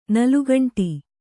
♪ nalugaṇṭi